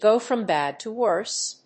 アクセントgò from bád to wórse